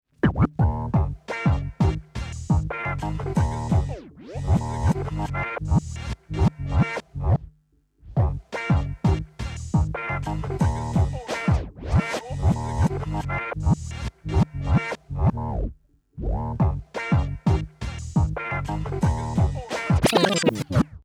Nahezu direktes Abbremsen und Starten stellt der Stanton sicher. Bei Doppeldruck auf den Button läuft die Platte rückwärts, was ein nützliches Feature für kreative Übergänge sein kann.
Stanton_STR8.150M2_Reverse-Test.mp3